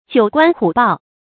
成語注音ㄐㄧㄨˇ ㄍㄨㄢ ㄏㄨˇ ㄅㄠˋ
成語拼音jiǔ guān hǔ bào
九關虎豹發音